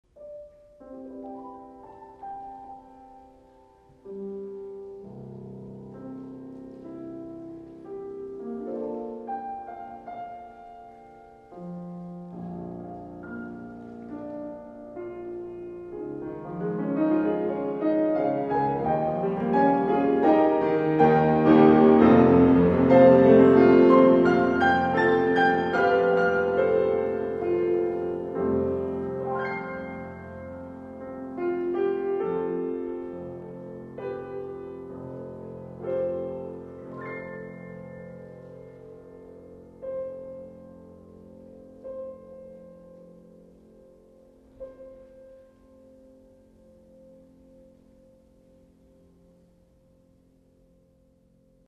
for Mixed Chrus, and Piano